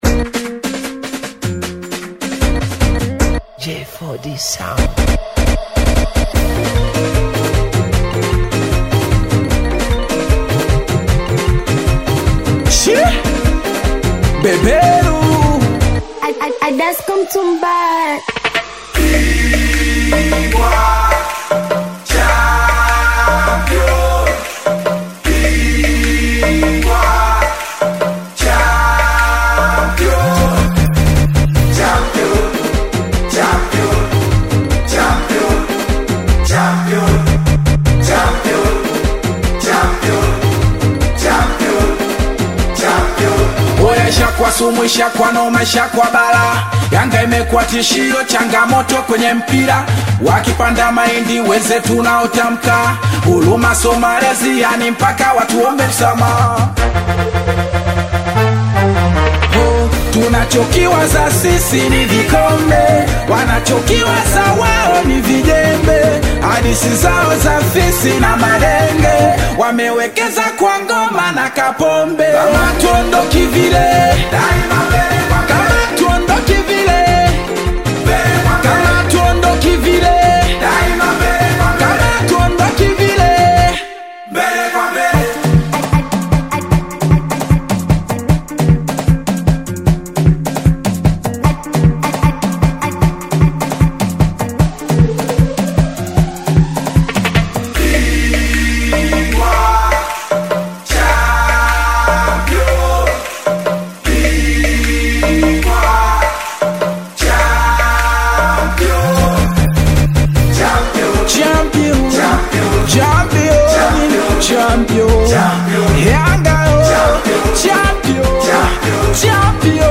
Singeli music track
Tanzanian Bongo Flava